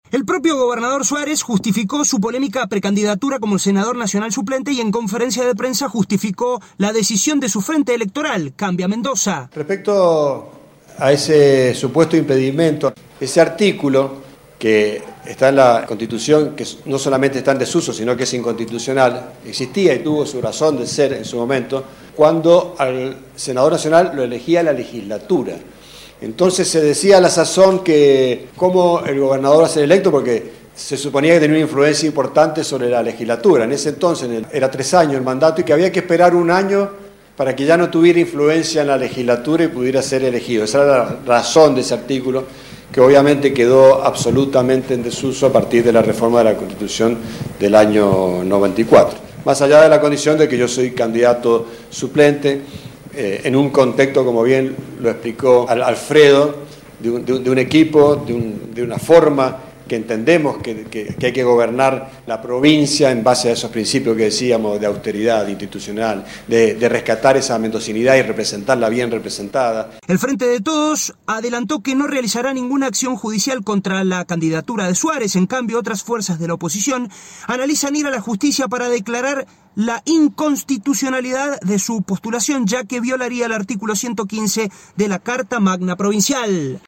El gobernador mendocino Rodolfo Suarez justificó su polémica precandidatura a senador nacional suplente y en conferencia de prensa justificó la decisión del frente electoral Cambia de Mendoza.
Informe